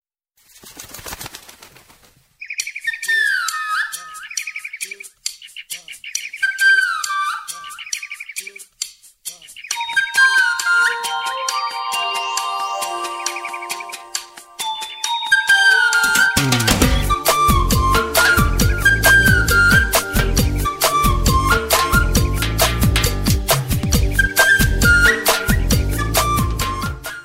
Category: Bird Ringtones